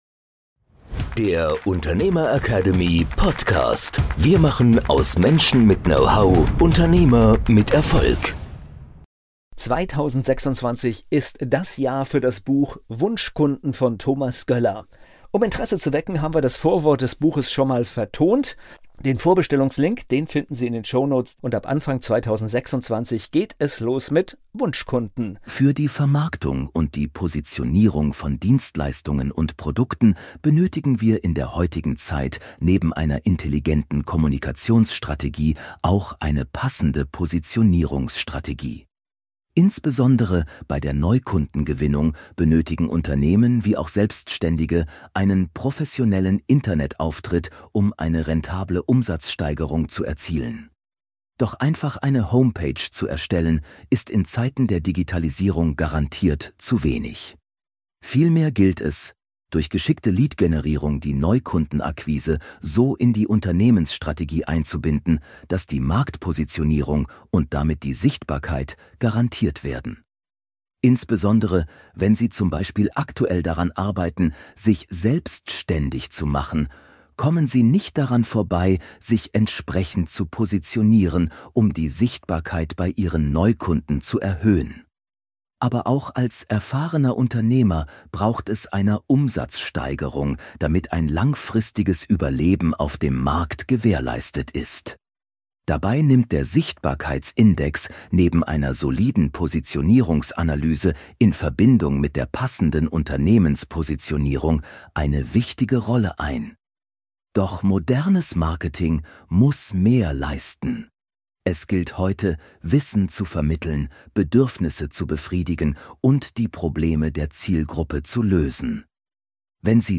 Vorwort des Buches eigens für diese Podcast-Folge eingesprochen –